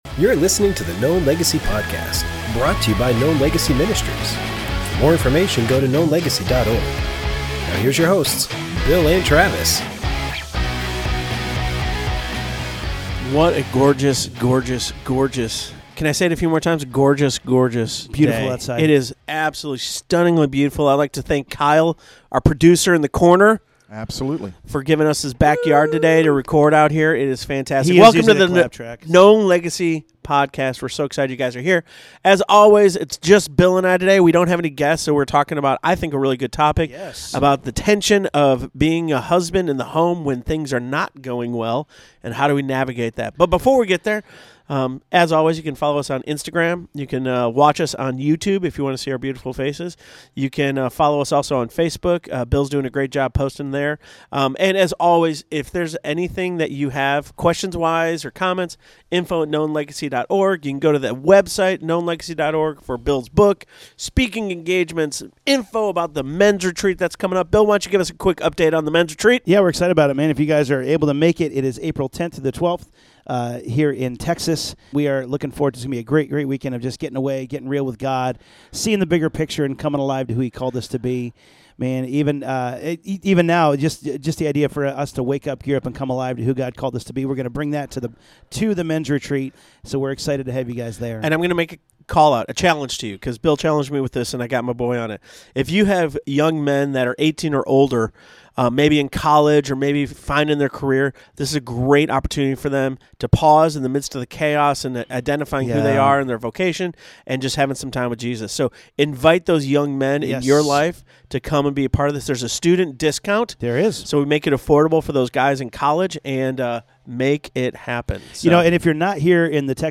This week on the podcast the guys dig deeper about the struggle that marriage can bring. They talk about how to keep communication open and how to stop passivity that can creep in as the years go by. if you are single or have been married for years, there is truth to be taken from this podcast.